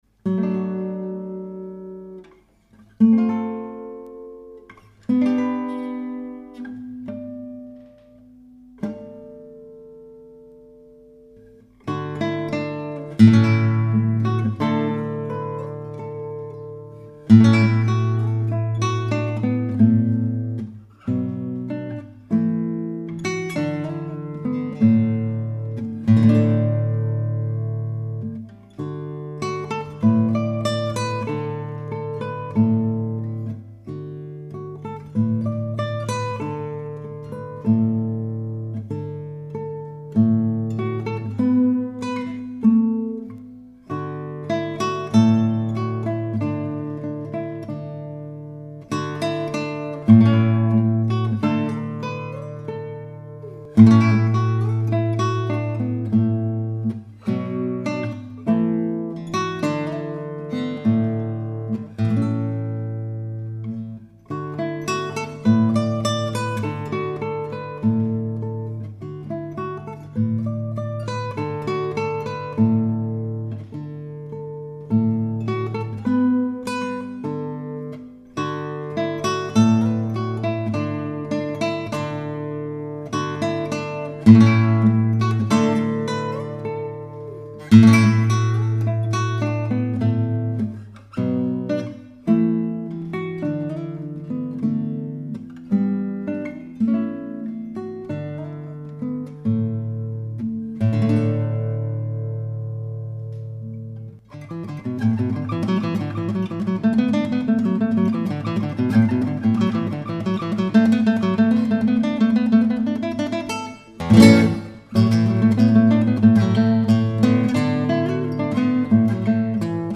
音乐风格:Pop/流行
音乐类型：国乐
以琵琶演奏技巧融入古典吉他
演绎出神入化 令人热血沸腾的临场感